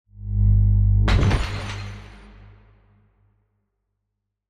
shield-spell-v1-outro-03.ogg